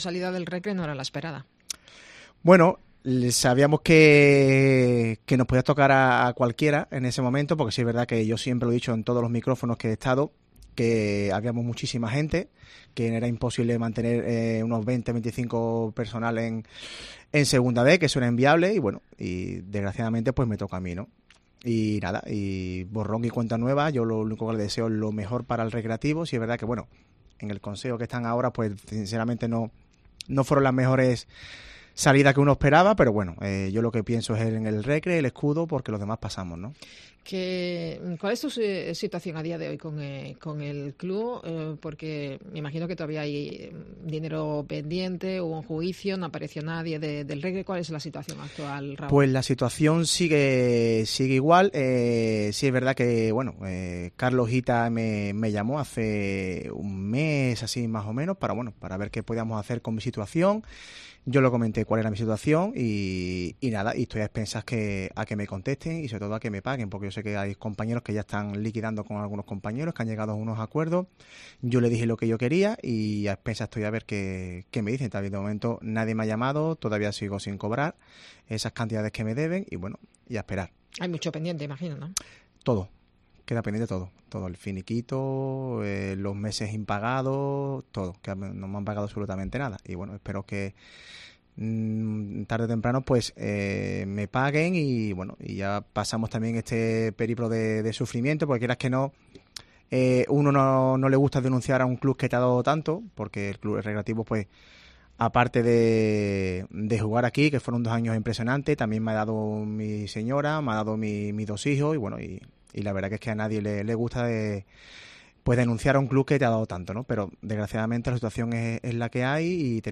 en los estudios de Cope Huelva